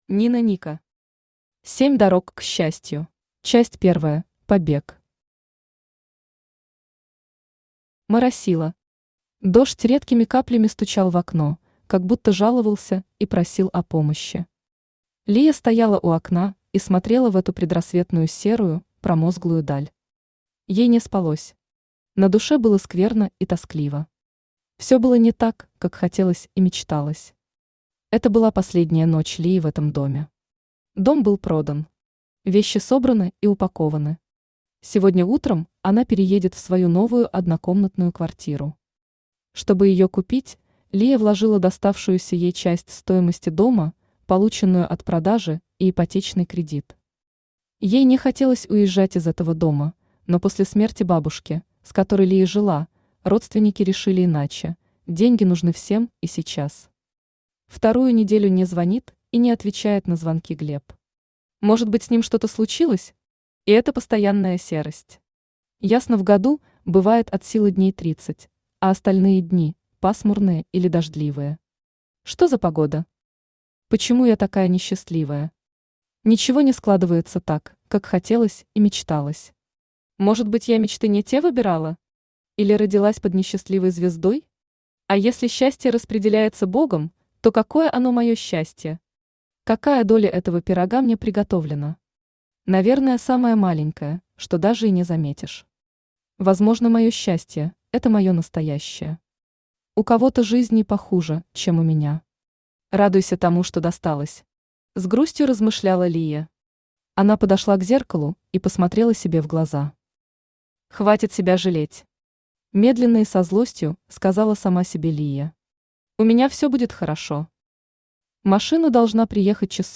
Аудиокнига Семь дорог к счастью | Библиотека аудиокниг